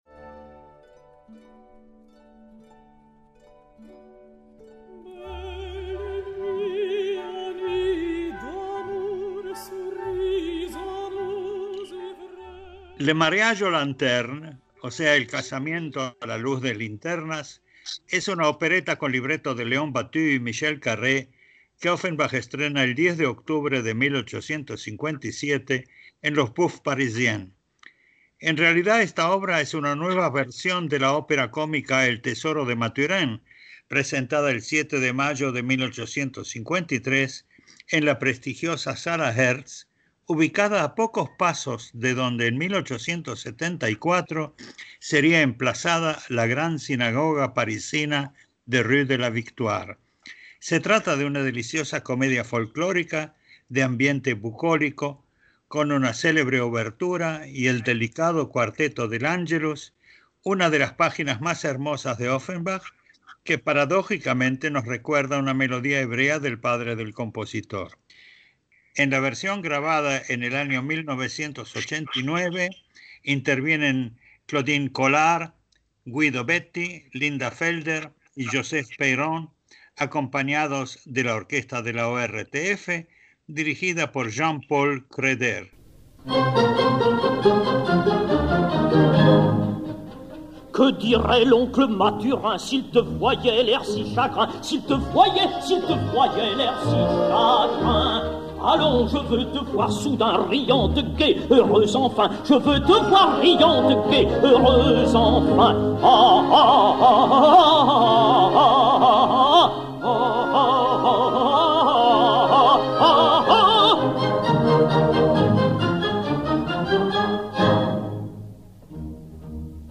En realidad esta obra es una nueva versión de la ópera cómica “El tesoro de Mathurin”, presentada el 7 de mayo de 1853, en la prestigiosa sala Herz, ubicada a pocos pasos de donde en 1874 sería emplazada la Gran Sinagoga parisina de Rue de la Victoire. Se trata de una deliciosa comedia folklórica, de ambiente bucólico, con una célebre obertura, y el delicado cuarteto del Angelus, una de las páginas más hermosas de Offenbach, que paradójicamente nos recuerda una melodía hebrea del padre del compositor.